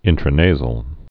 (ĭntrə-nāzəl)